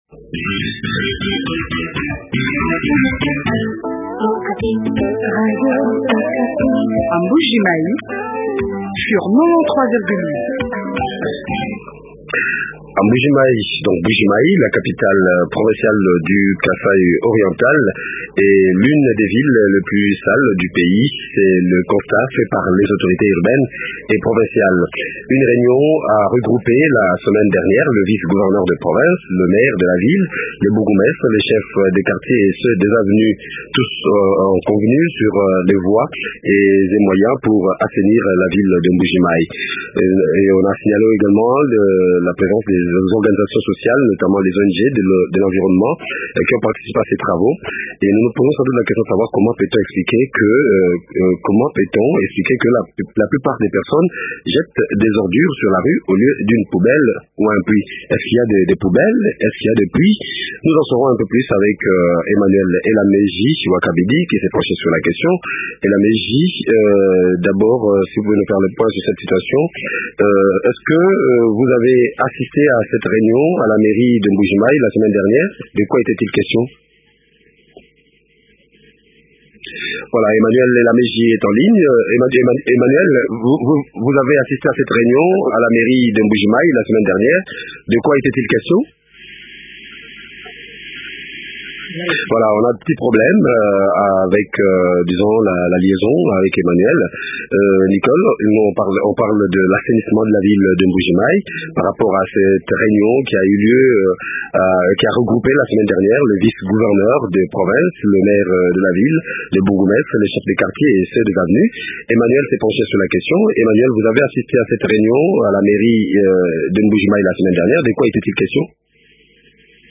Tous ont examiné les voies et moyens pour assainir la ville de Mbuji mayi. Que faire pour changer la mentalité de la population? Marcel Innocent Kingwa, Maire de la ville de mbujimayi est reçu